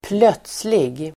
Uttal: [²pl'öt:slig]